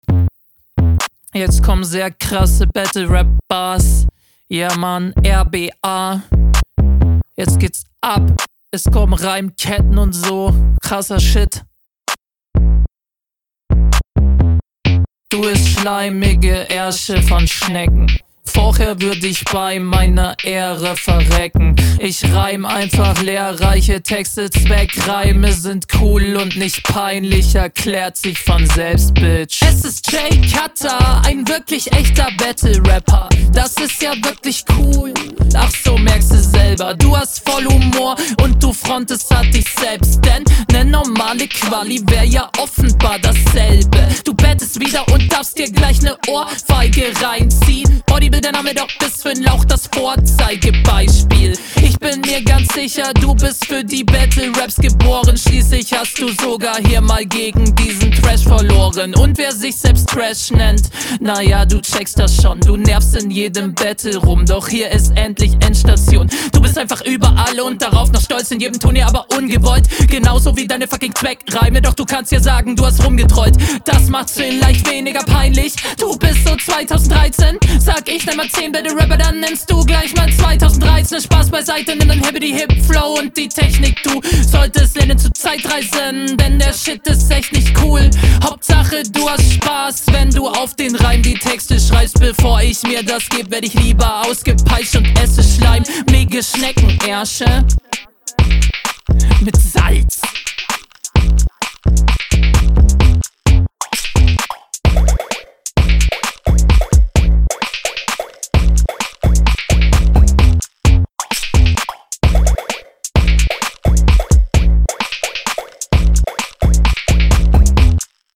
Außergewöhnlicher Flow deine Stimme hat Wiedererkennungswert.
die doubles sind bisschen komisch
😂 Deine Stimme klingt sehr hell.